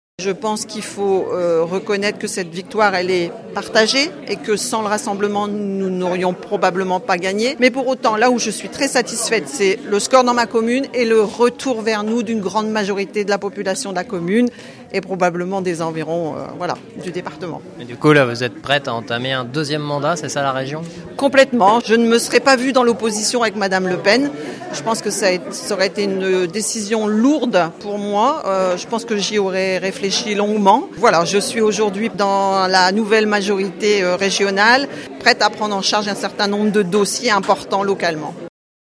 réaction de Paulette Juilien-Peuvion réélue conseillère régional dans le boulonnais